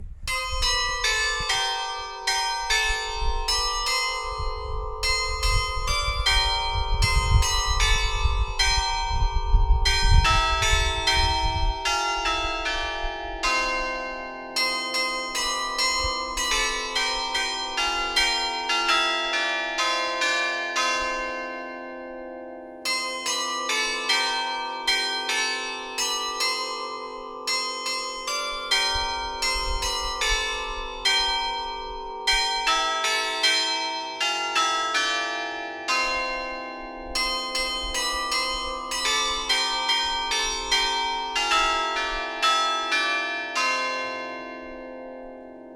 Glockenspiel in Steinhausen (ZG)
Die 13 Glocken sind an einem Aussengerüst frei vor die Fassade gesetzt.
Diese sind fix montiert und werden mit magnetischer Steuerung bespielt.
Wenn du auf die Glocken klickst, hörst du, wie das Carillon Steinhausen klingt. Der Örtlichkeit geschuldet, ist es ein Kirchenlied.
Kirchenlied_Steinhausen.mp3